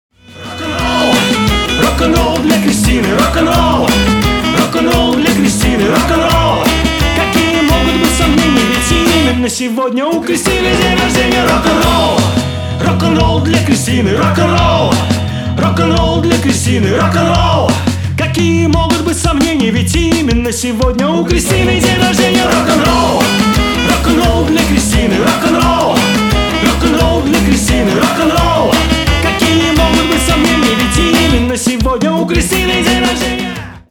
• Качество: 320 kbps, Stereo